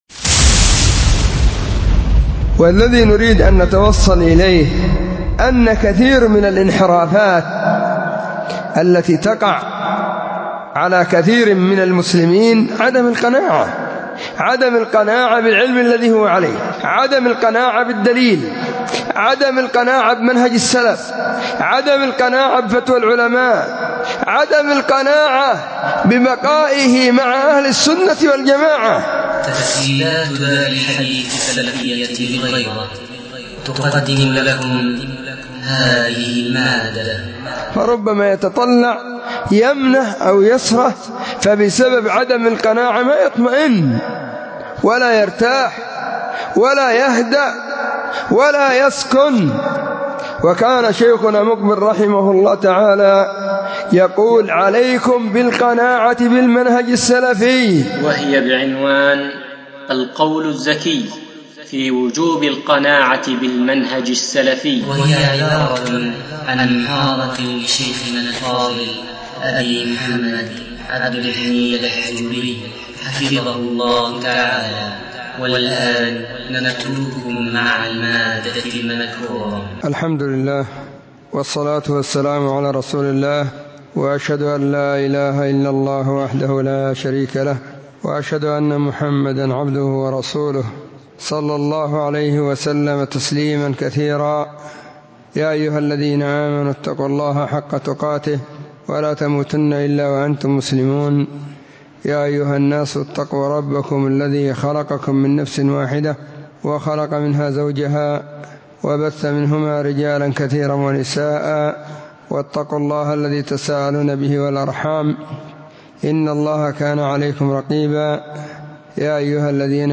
محاضرة بعنوان *القول الزكي في وجوب القناعة بالمنهج السلفي *
📢 مسجد الصحابة – بالغيضة – المهرة، اليمن حرسها الله،